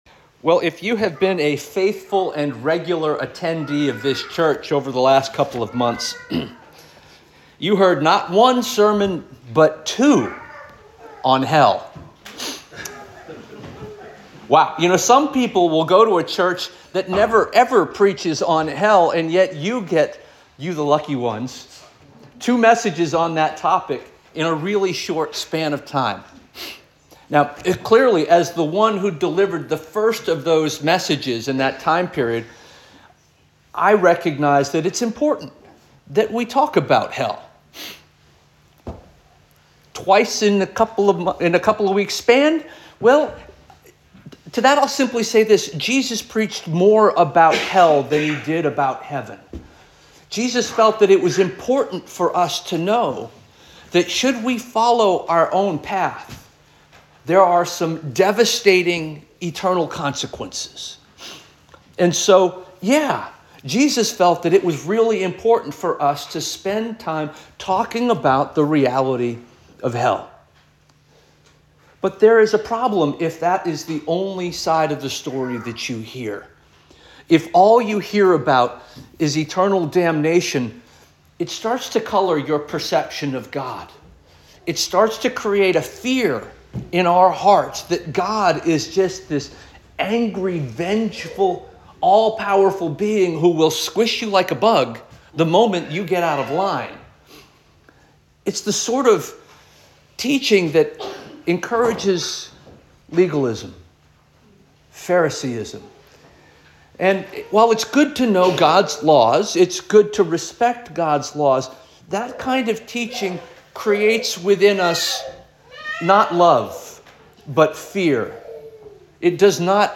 December 1 2024 Sermon